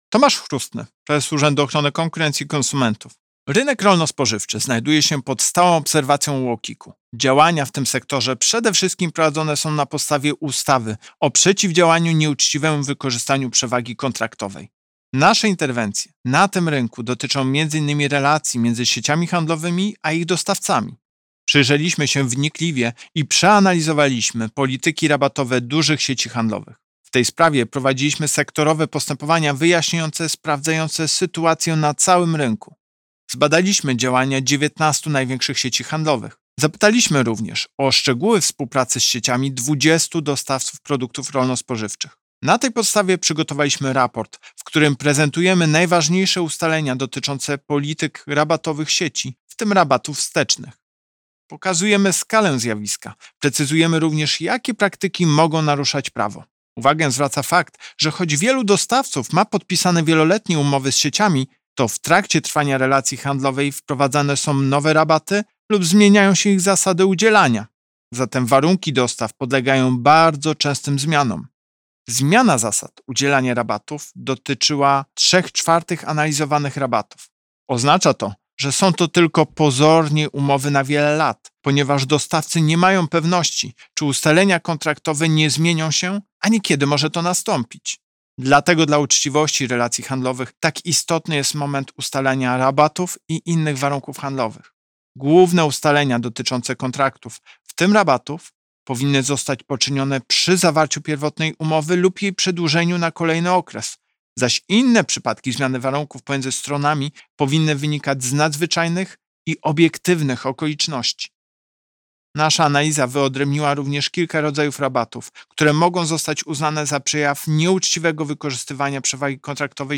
Pobierz wypowiedź Prezesa UOKiK Tomasza Chróstnego - Przyjrzeliśmy się wnikliwie i przeanalizowaliśmy polityki rabatowe dużych sieci handlowych.